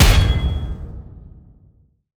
rank-impact-fail.wav